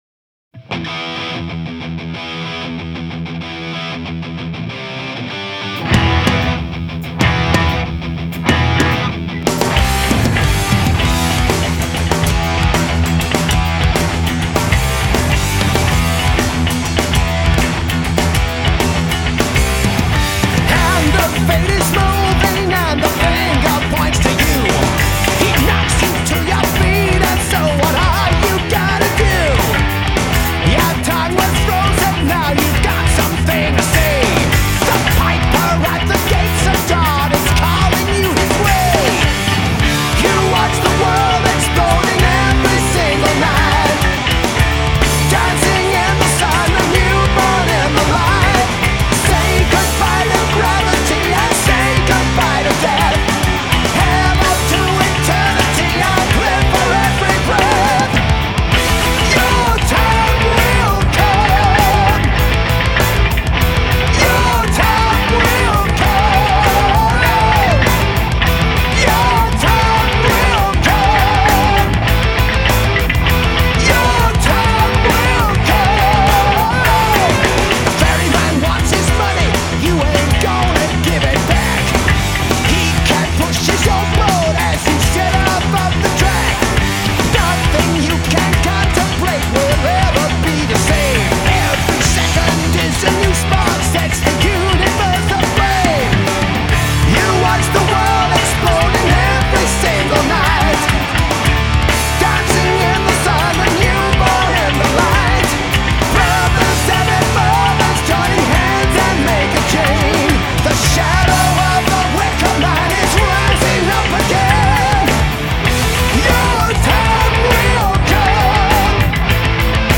Heavy Metal, Live Album